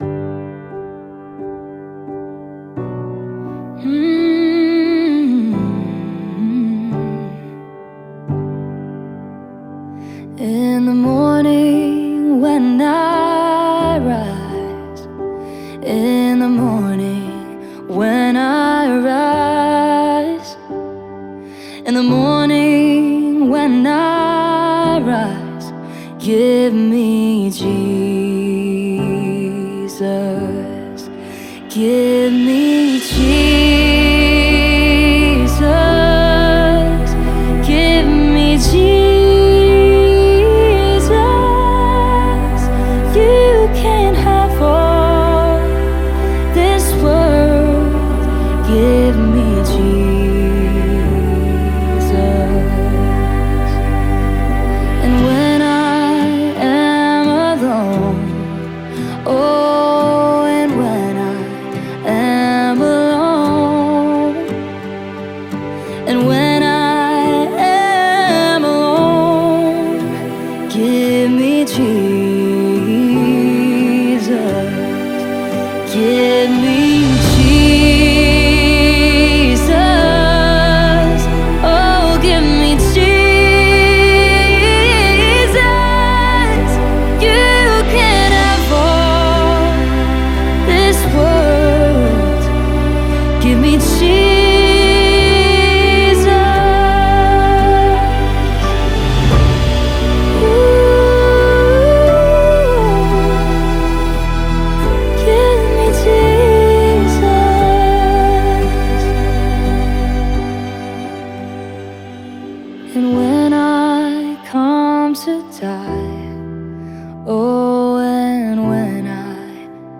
Universal Gospel